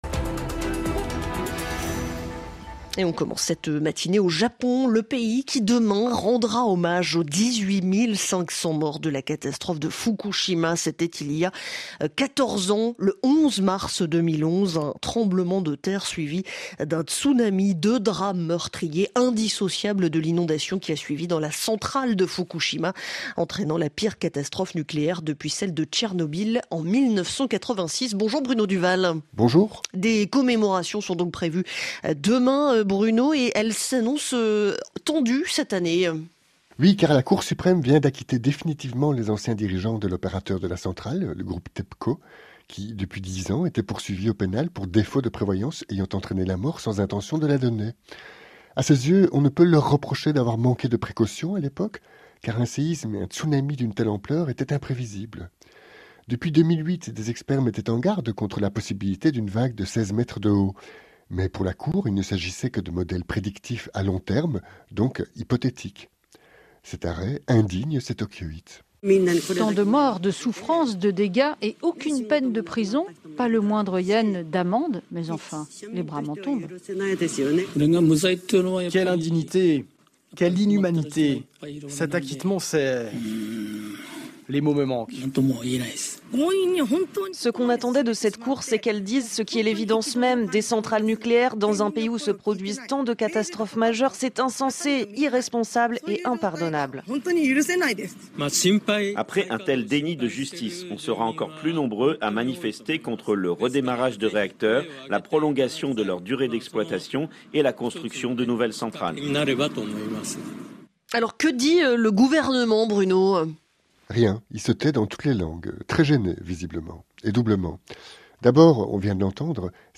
Du lundi au vendredi, retrouvez ce qui fait la Une de l'actualité en Asie aujourd'hui, avec les journalistes du service international et les correspondants de RFI ainsi que nos correspondants sur le continent.